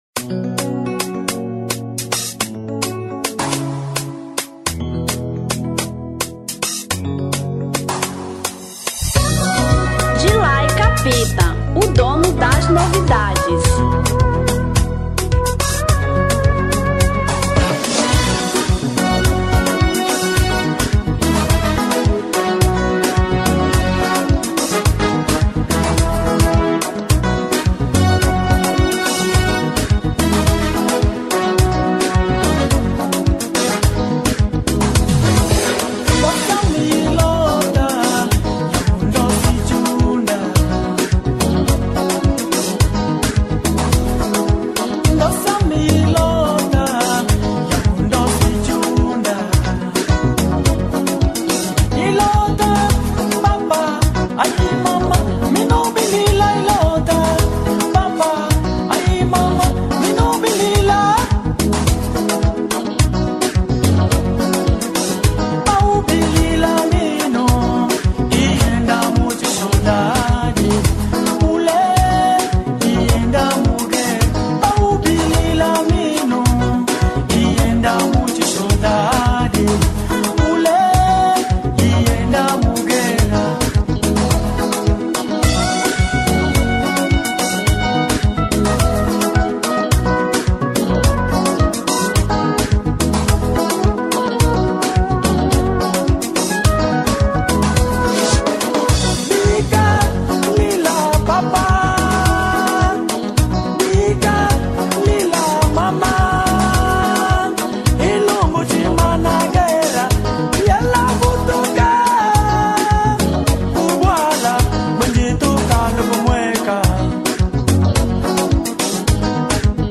Kizomba 2005